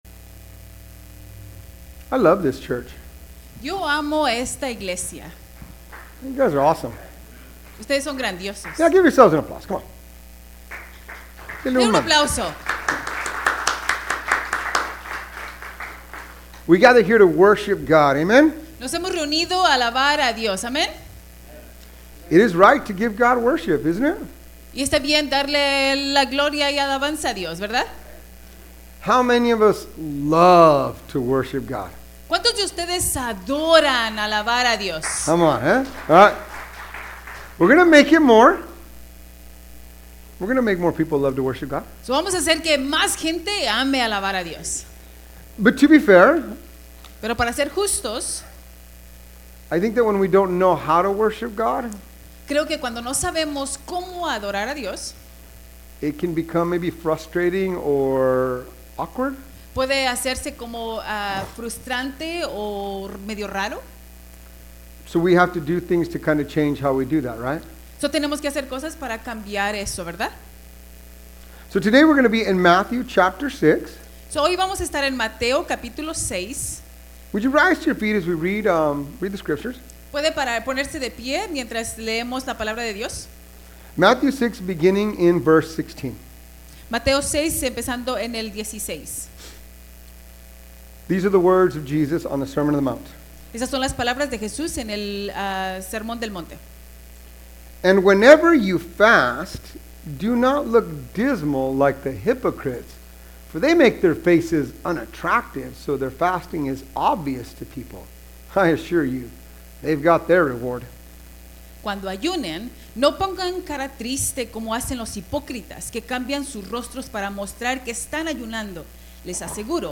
We wanted to extend a warm welcome to everyone who joined us for our bilingual service on Sunday!